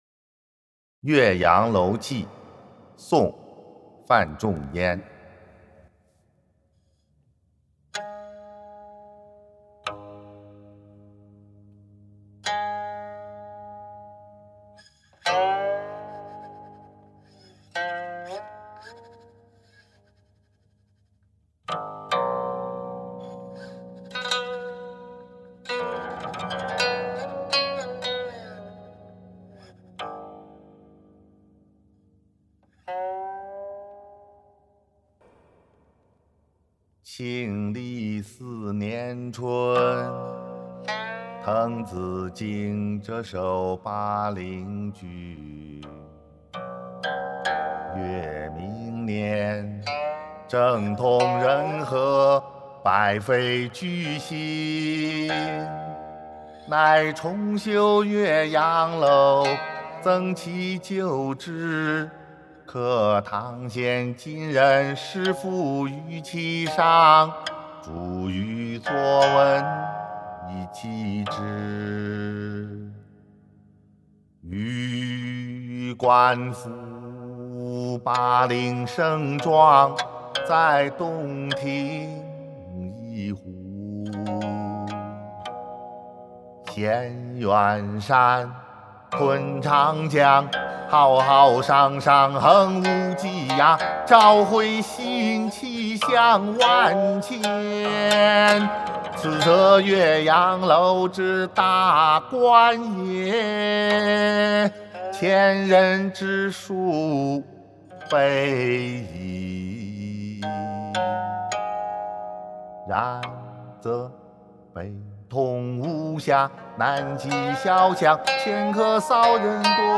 ［宋］范仲淹 《岳阳楼记》（吟咏）